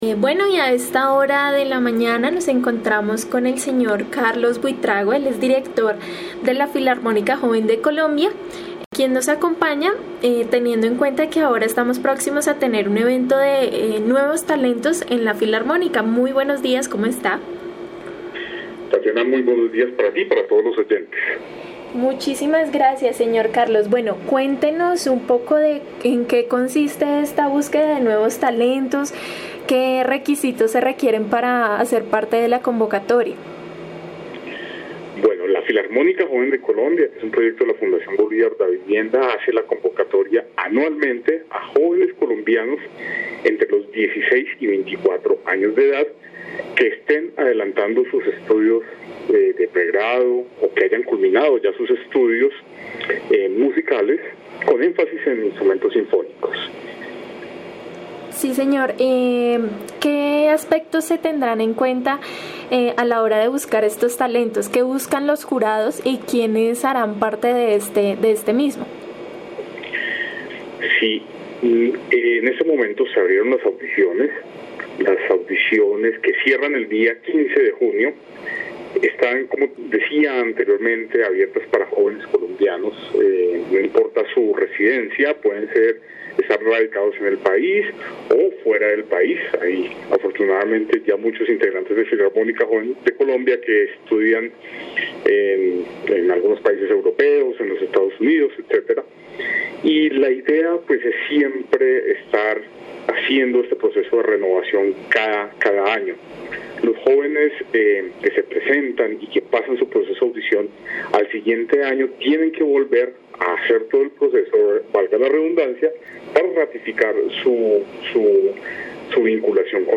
en diálogo con Uniminuto Radio